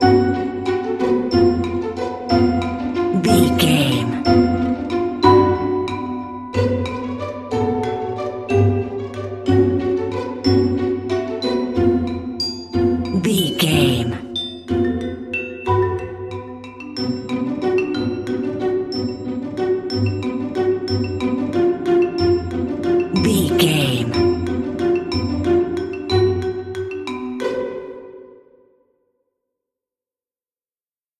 Lydian
strings
orchestra
percussion
circus
comical
cheerful
perky
Light hearted
quirky